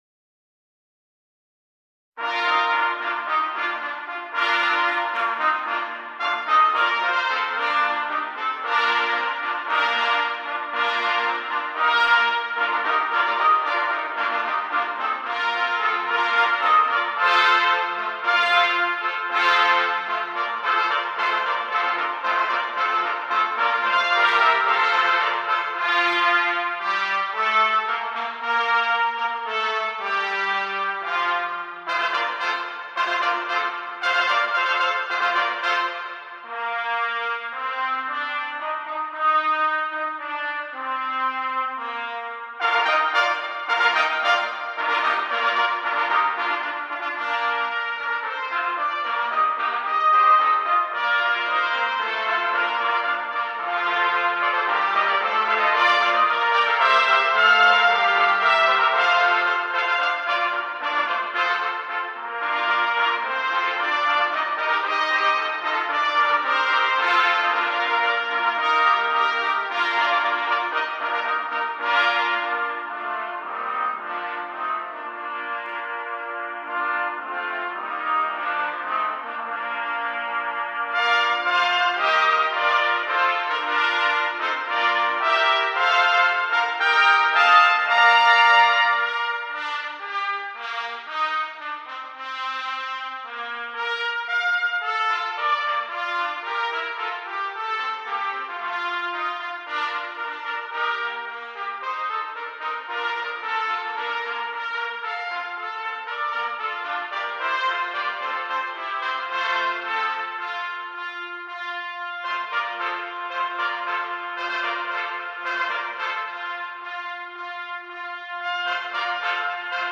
Number of Trumpets: 10
Key: Bb concert
…a welcomed work for trumpet ensembles.